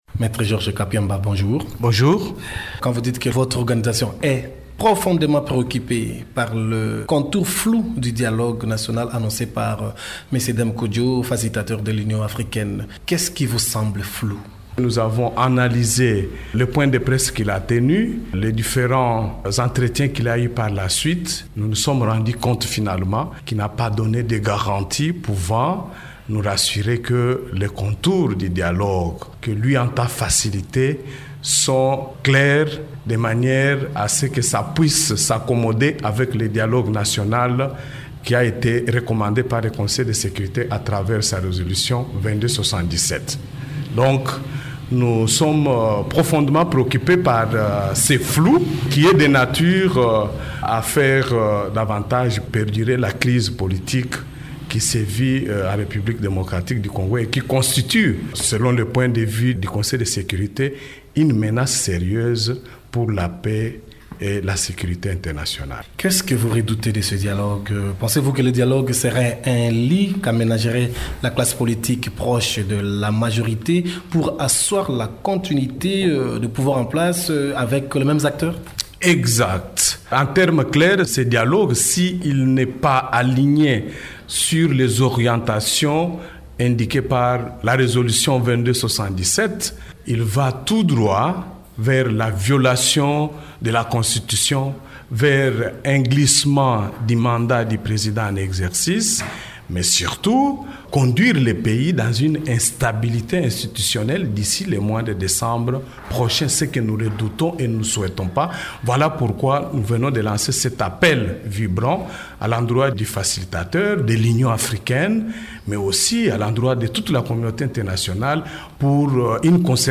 a indiqué dans une interview accordée à Radio Okapi que la plate-forme de 33 ONG de défense des droits de l’homme était «prête à participer au dialogue qui s’inscrit dans le cadre de la résolution 2277».